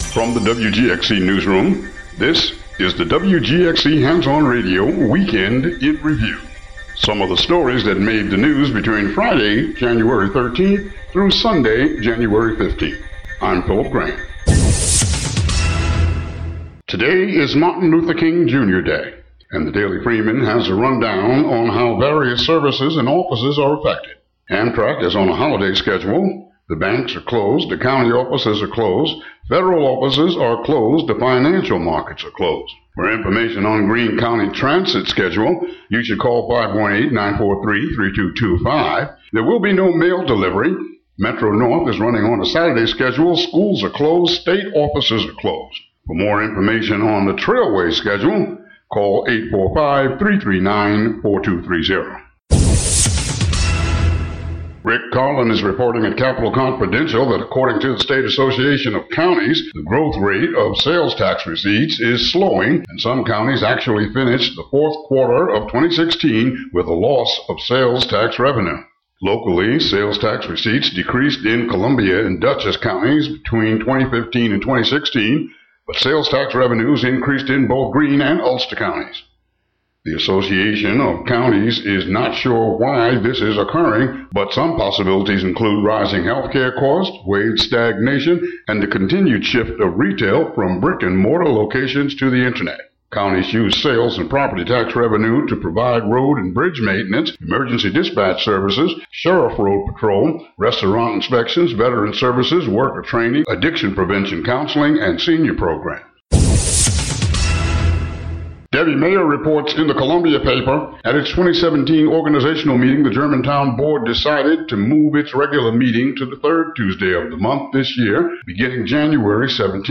WGXC daily headlines for Jan. 16, 2017.